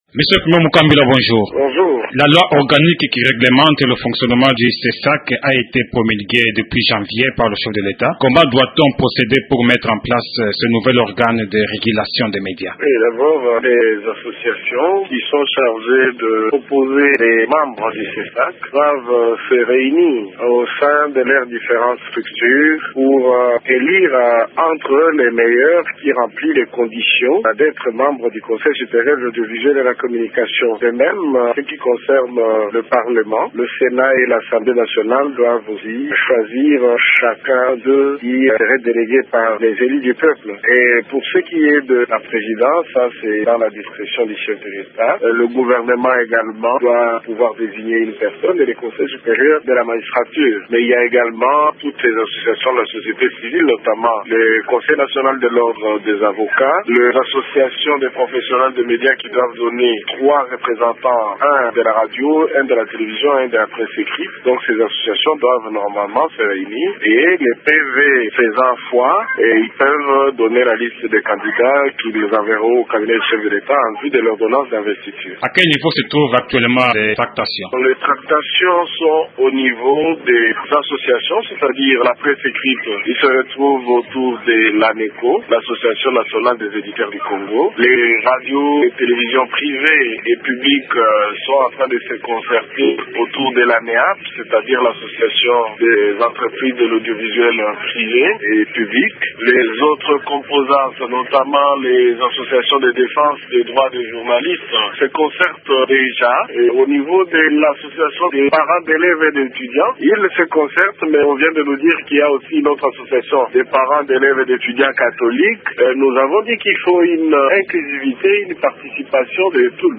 Après que le chef de l’Etat, Joseph Kabila ait promulgué la loi organique portant fonctionnement du Conseil supérieur de l’audiovisuel et de la communication (CSAC), quelle est la procédure à suivre pour désigner les 15 membres devant faire partie du nouvel organe de régulation des médias ?Le président intérimaire du CSAC et président de l’ex Haute autorité des médias répond aux questions de Radio Okapi.